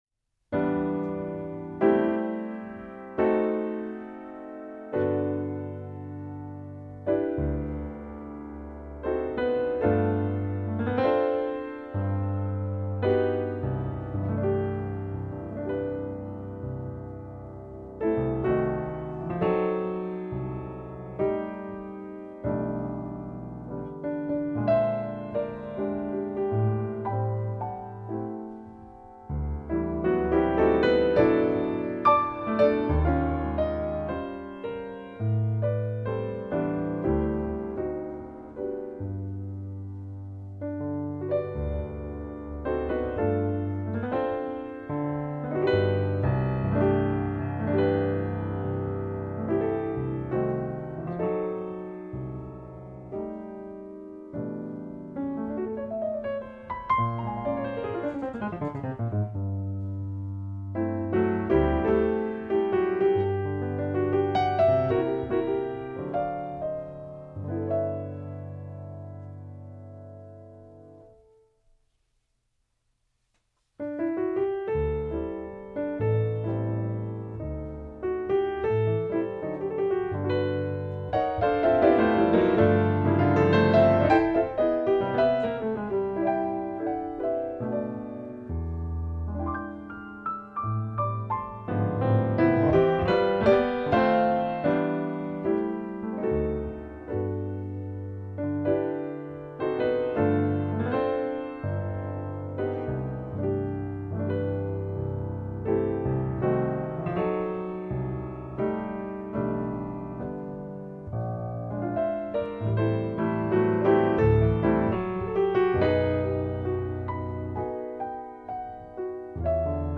on a Yamaha C6 Grand Piano
in Asheville, NC